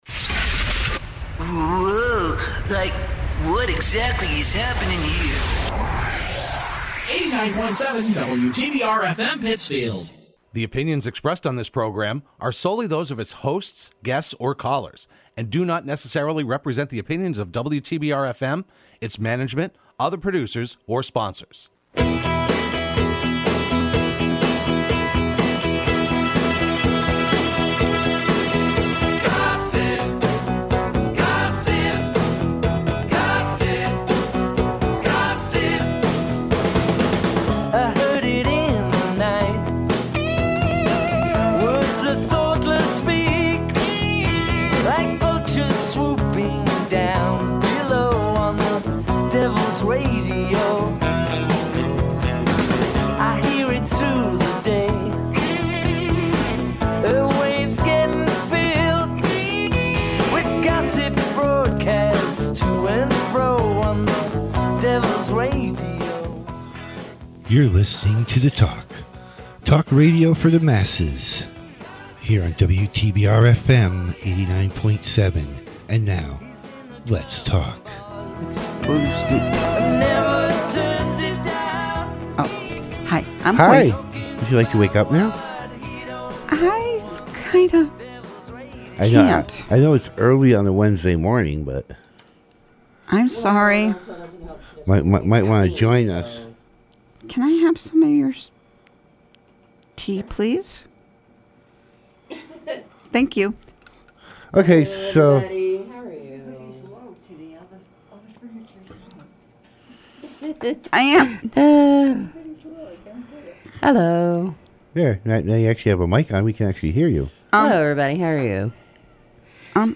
Broadcast every Wednesday morning at 9:00am on WTBR.